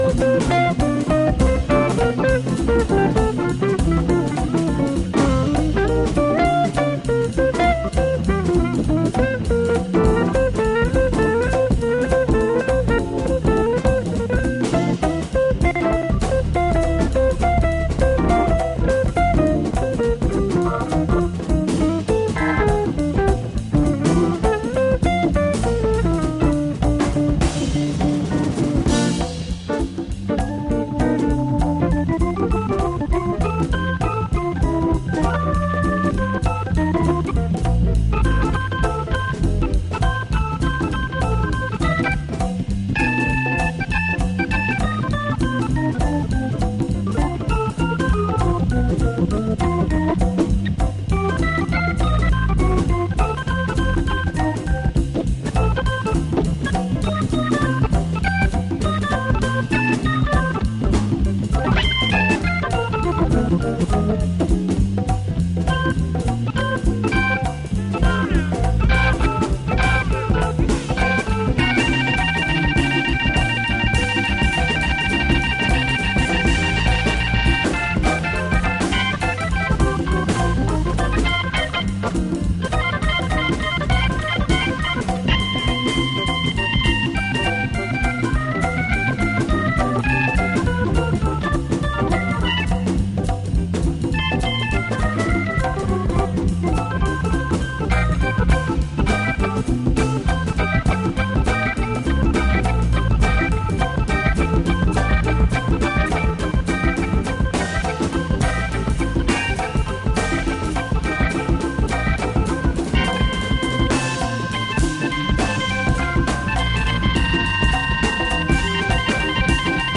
soul-jazz